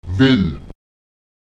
Lautsprecher wel [wEl] wir, uns (1. Person Plural)